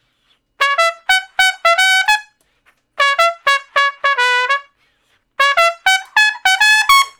087 Trump Straight (Db) 05a.wav